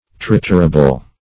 Search Result for " triturable" : The Collaborative International Dictionary of English v.0.48: Triturable \Trit"u*ra*ble\, a. [Cf. F. triturable.] Capable of being triturated.
triturable.mp3